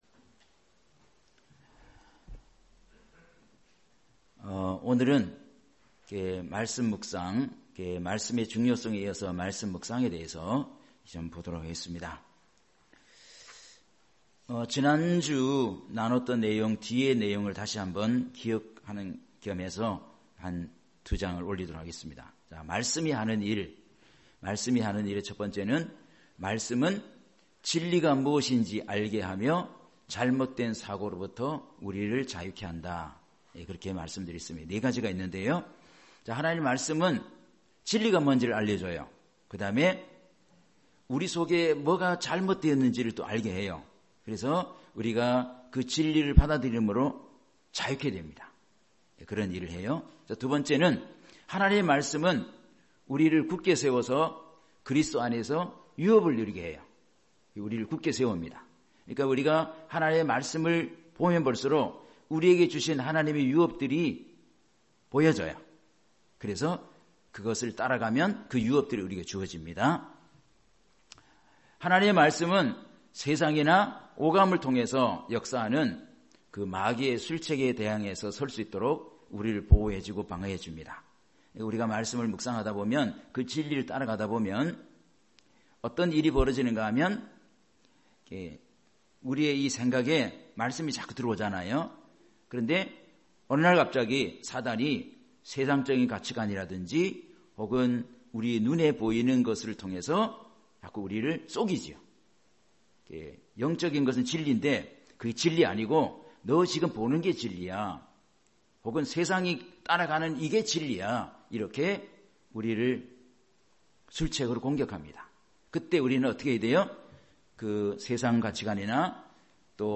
주일 오전 말씀